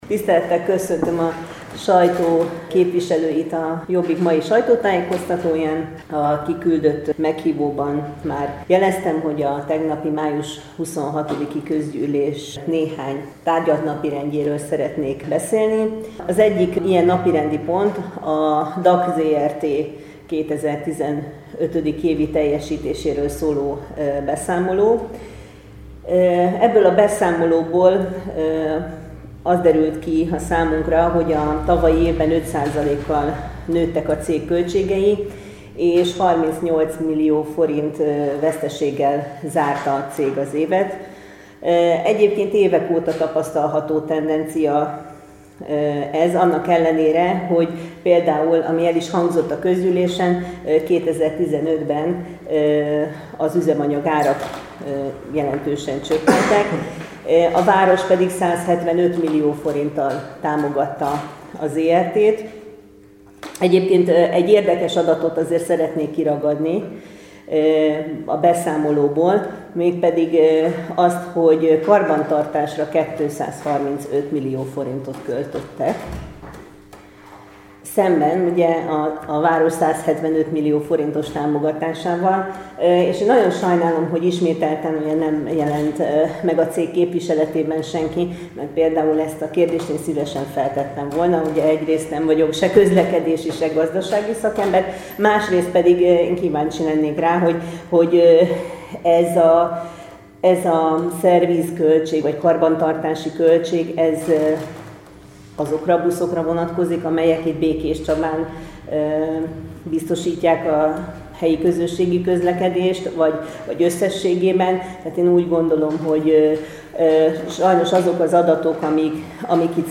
Sajtótájékoztatón értékelte a városi közgyűlésen történteket a Jobbik - Körös Hírcentrum